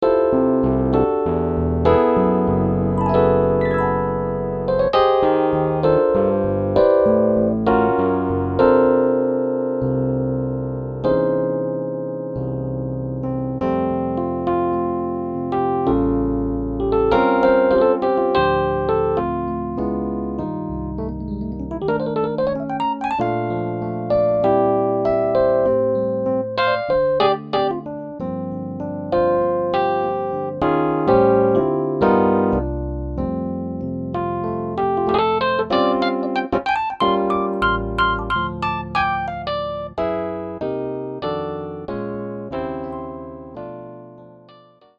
Звучание роялей Shigeru Kawai SK-EX и Kawai EX, воссозданное с помощью технологии формирования звука Harmonic Imaging